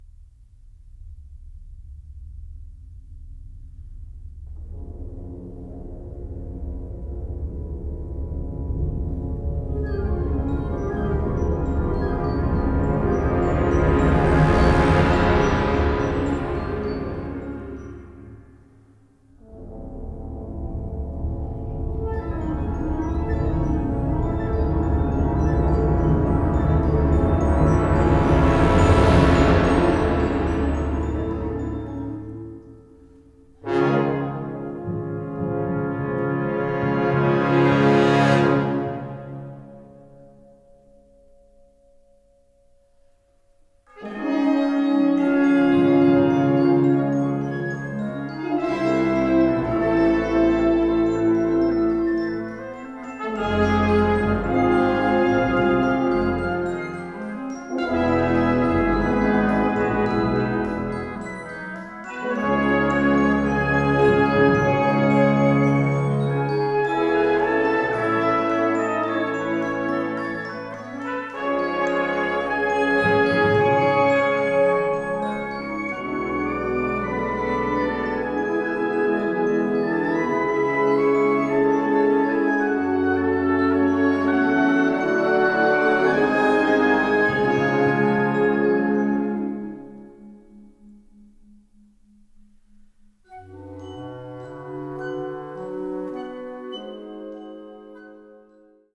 Catégorie Harmonie/Fanfare/Brass-band
Sous-catégorie Musique contemporaine (1945-présent)
Instrumentation Ha (orchestre d'harmonie)